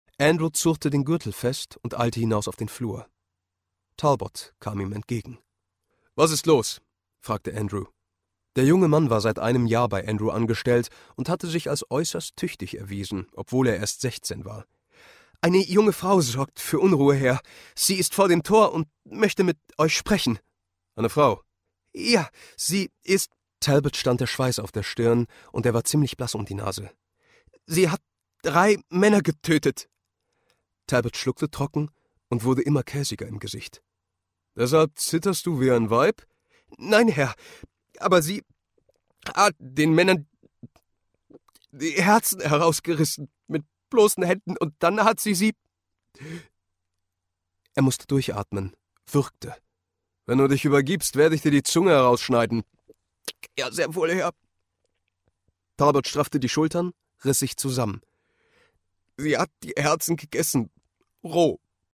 Easy Jet werbung